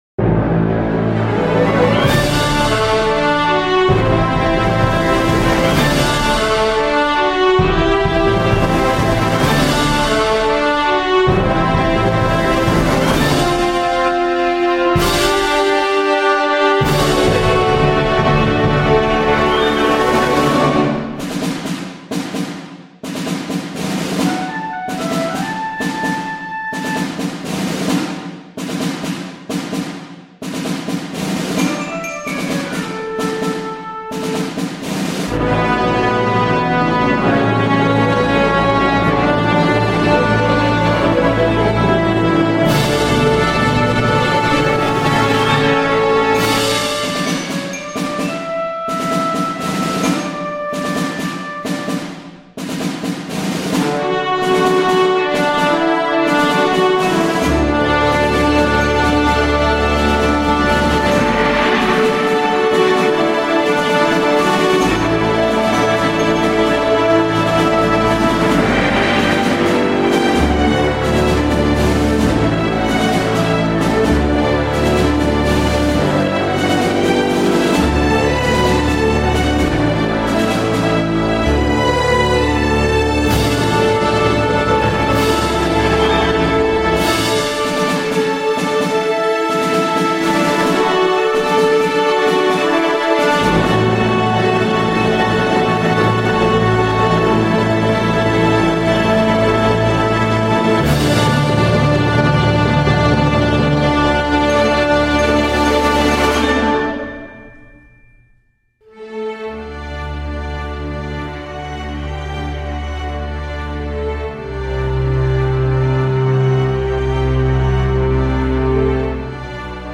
String Section
Horns
Mallet Percussion
Trombones